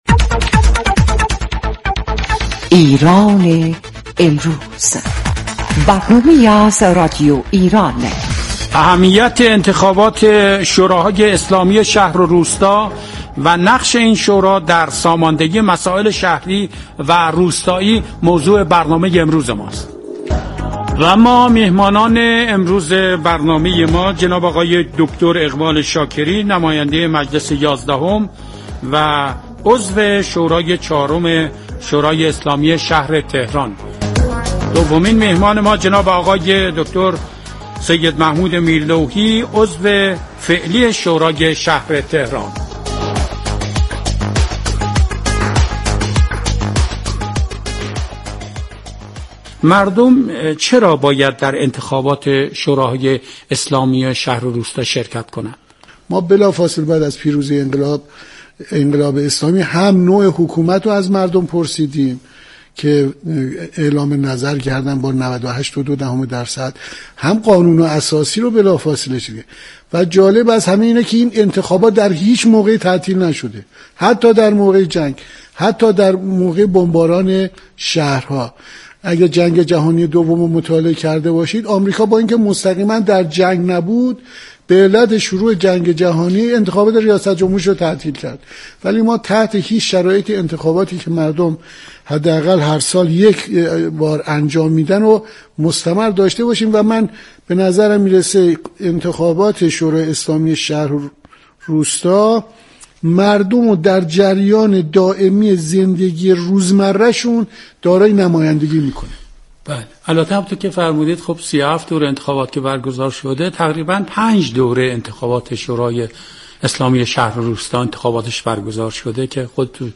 در هفدهمین برنامه انتخاباتی «ایران امروز»، سه شنبه 18 خرداد، میزبان اقبال شاكری، نمایندۀ مجلس یازدهم و عضو دورۀ چهارم شورای شهر تهران و سید محمود میرلوحی، عضو فعلی شورای شهر تهران بود.